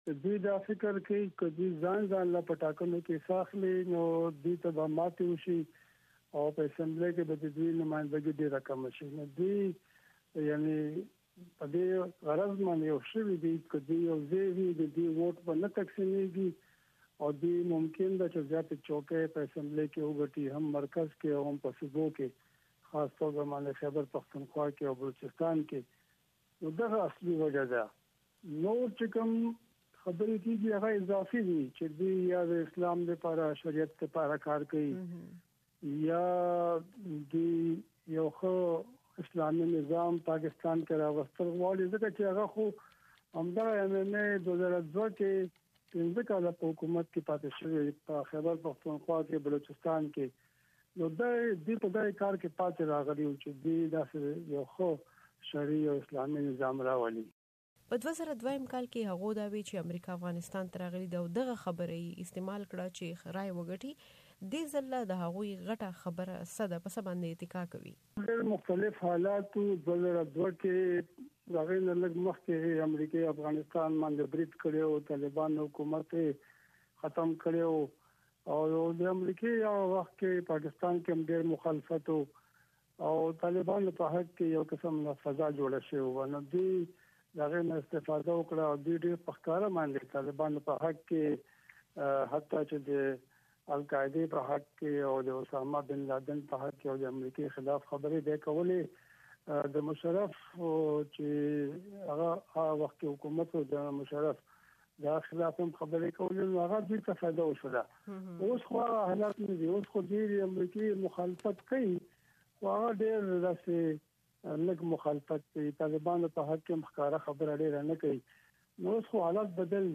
د رحیم الله یوسفزي سره مرکه